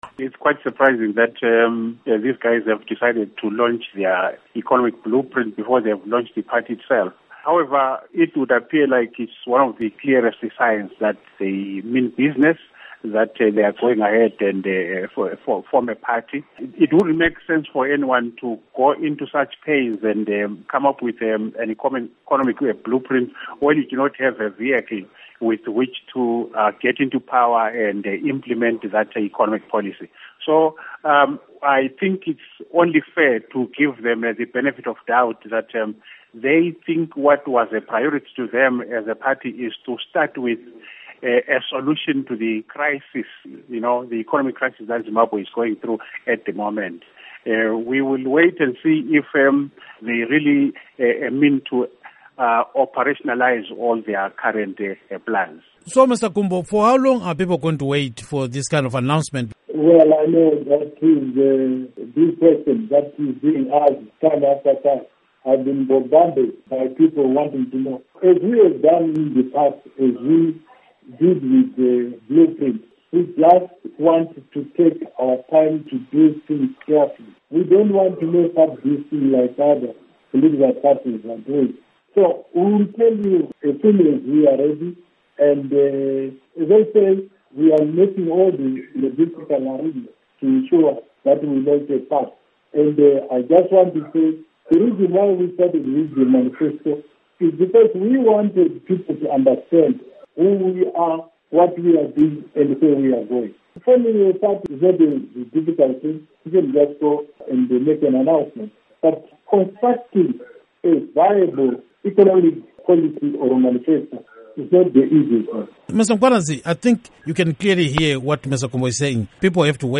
Panel Interview